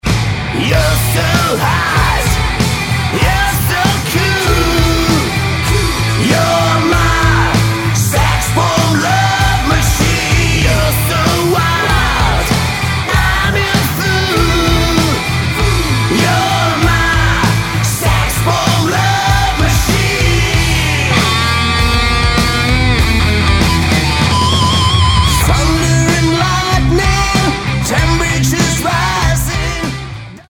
Lead Vocals
Guitar
Drums